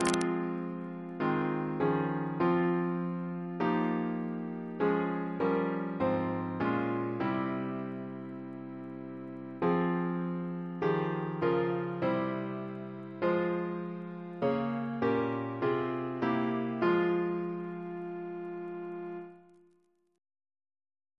Double chant in F minor Composer: Chris Biemesderfer (b.1958) Note: for Psalm 91